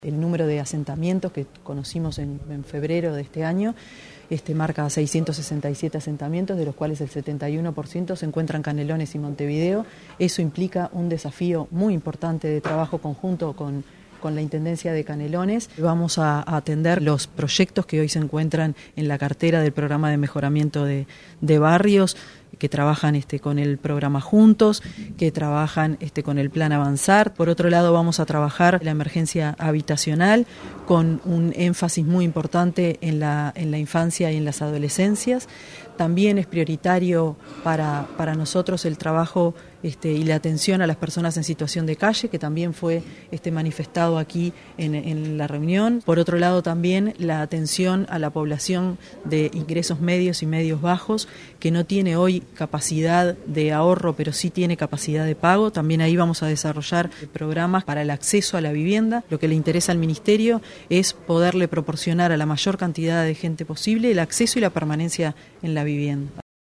Canelones y Montevideo suman 667 asentamientos, lo que supone un 71% de todo el país, implicando "un desafío importante para el Ministerio de Vivienda y la Intendencia", dijo la Ministra Tamara Paseyro quien se reunió con el Intendente Legnani y su equipo.